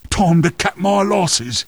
Worms speechbanks
Nooo.wav